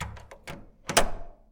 ドア閉める外から
cls_hotel_door2.mp3